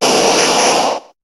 Cri de Tortank dans Pokémon HOME.